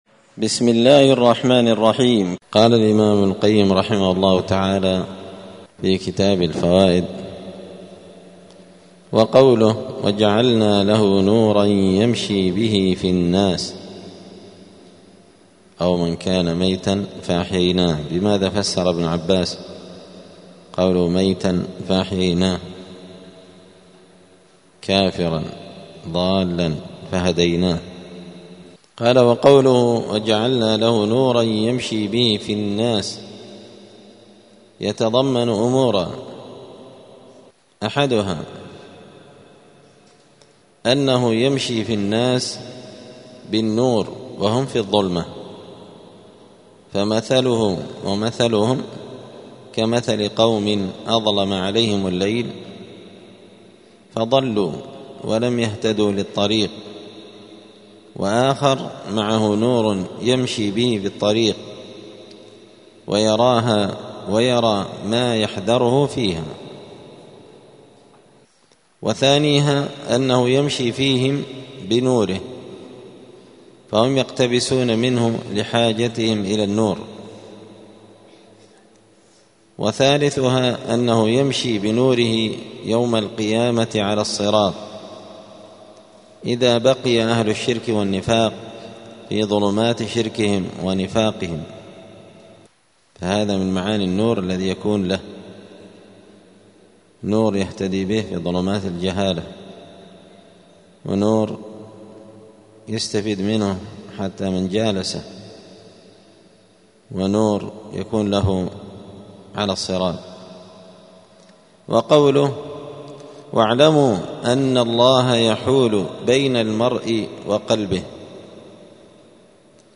*الدرس التاسع والأربعون (49) {فصل: وجعلنا له نورا يمشي به في الناس}*